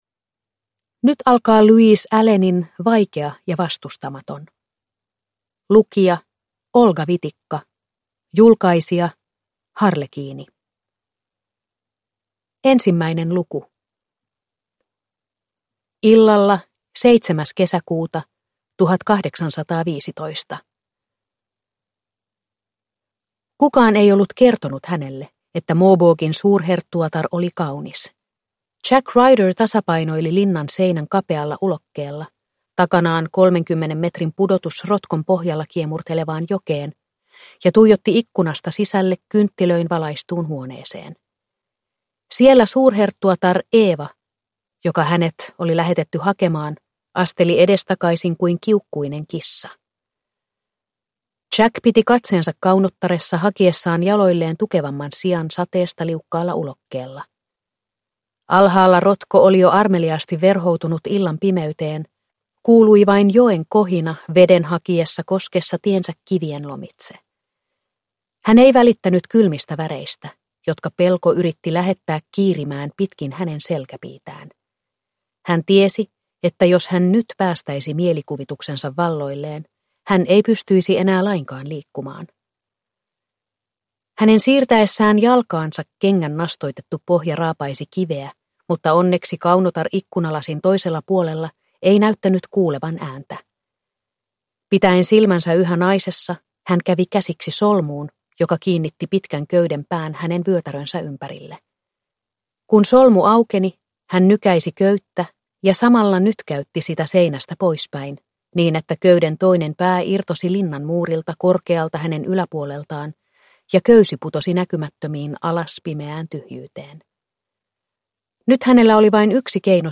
Vaikea ja vastustamaton – Ljudbok – Laddas ner